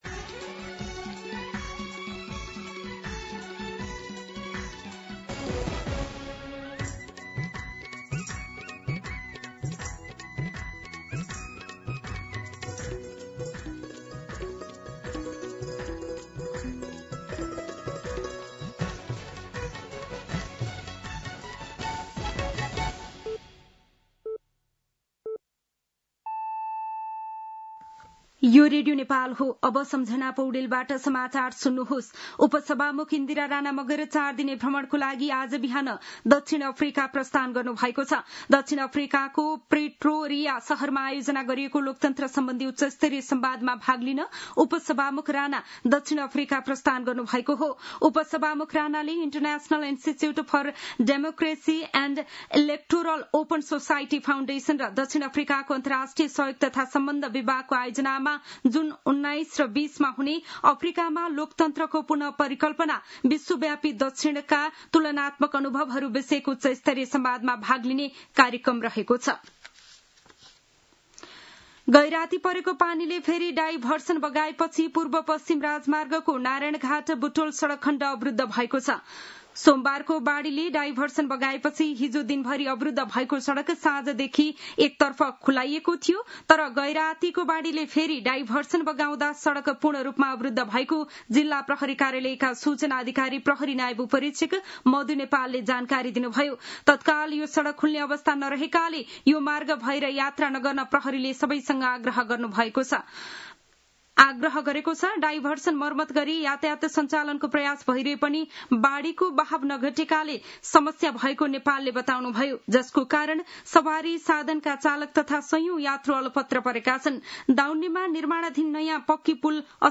मध्यान्ह १२ बजेको नेपाली समाचार : ४ असार , २०८२